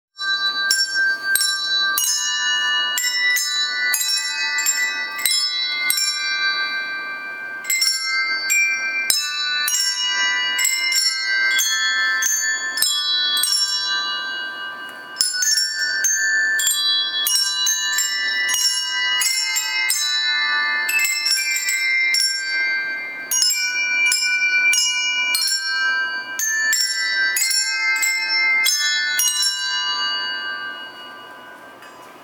本日、音楽部の部活動体験が行われました。
ほんの一部ではありますが、 オープニングで披露したハンドベルの演奏をお聞きください。 音楽部「ふるさと」（ミュージックベル） また、小学生・職員に披露した演奏は、 明日の「第68回社会を明るくする運動」の公演でも披露する予定です。